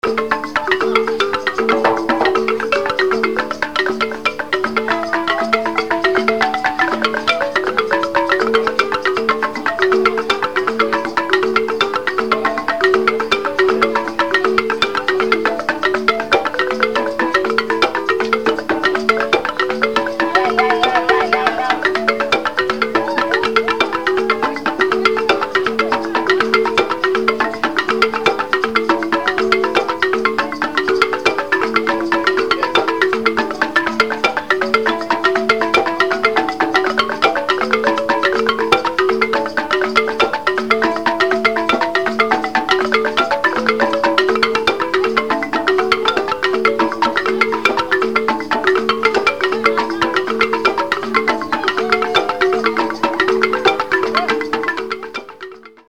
Tiga Be Mvamba est un groupe culturel Camerounais, défendant l’héritage ancestral et spécialisé dans le chant et la danse. Les animations proposées sont religieuses, avec une chorale, et folkloriques, dans le style traditionnel.
Echantillons de musiques Camerounaises